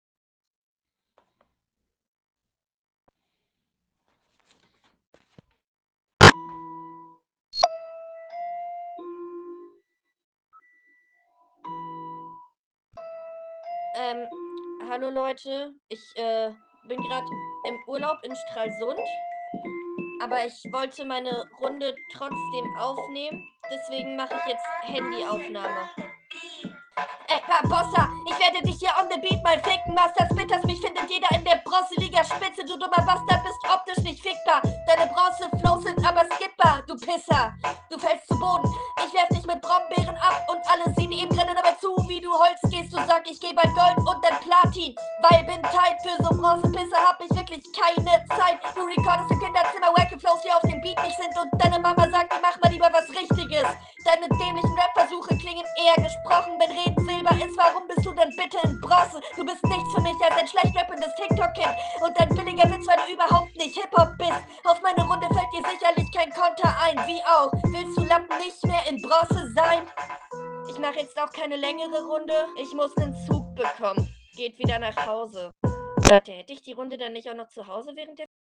Ja die Soundquali ist die Soundquali. Flowlich auch ähnlich wie sonst.